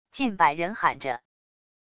近百人喊着wav音频